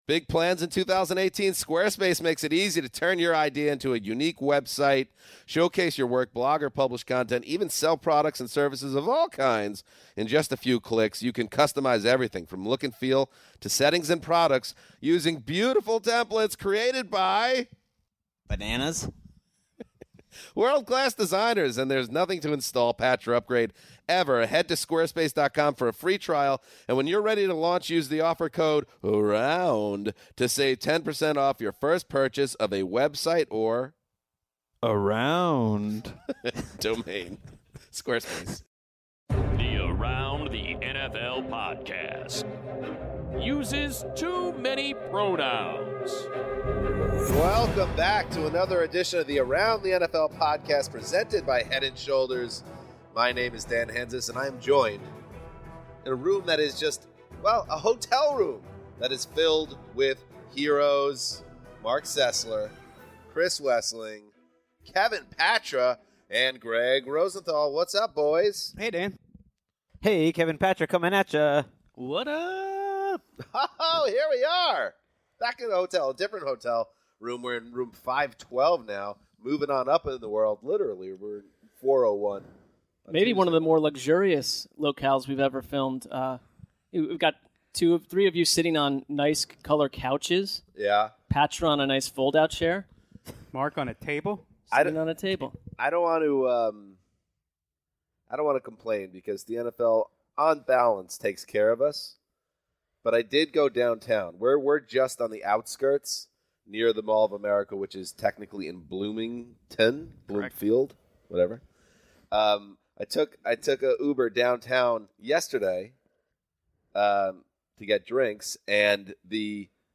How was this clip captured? bring you the ultimate Super Bowl Preview Extravaganza straight from Room 519 of an undisclosed hotel.